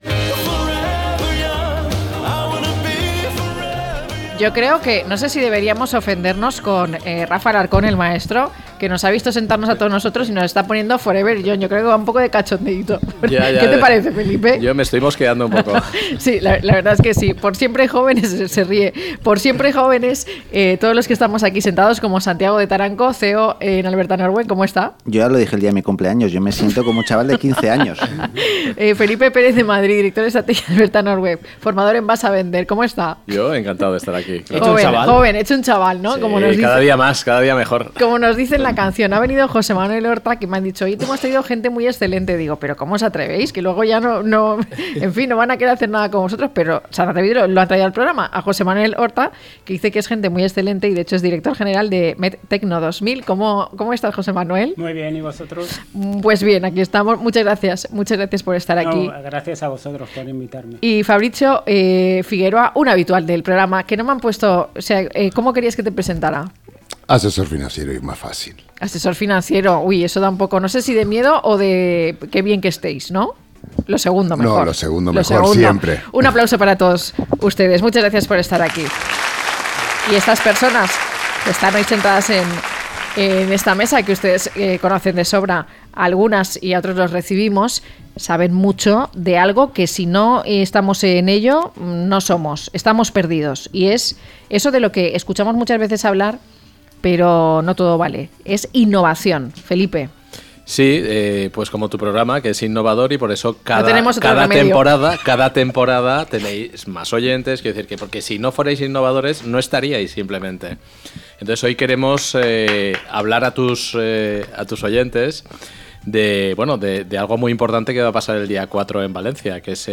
0630-LTCM-TERTULIA.mp3